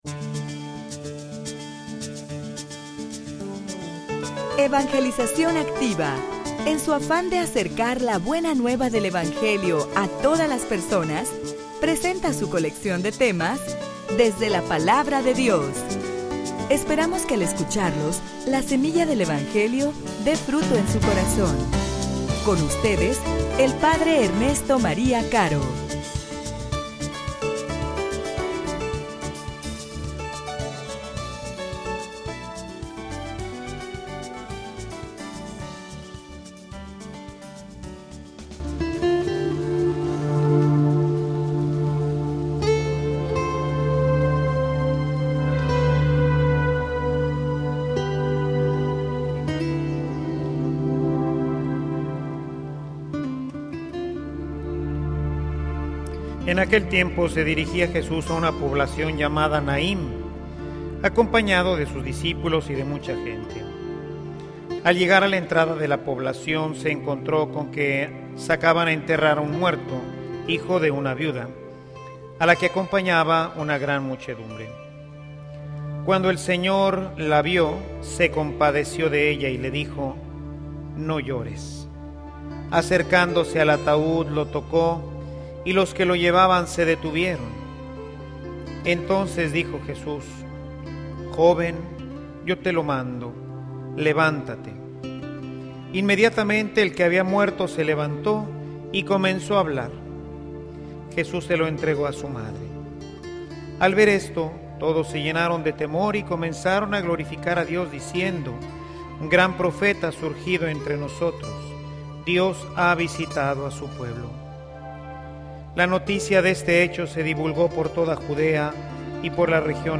homilia_Y_tu_que_haces_por_los_demas.mp3